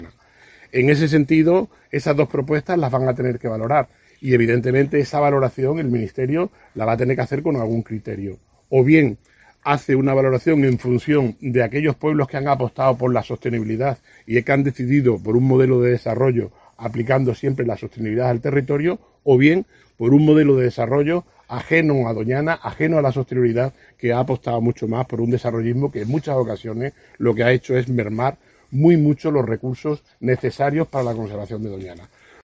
Paco Bella, alcalde de Almonte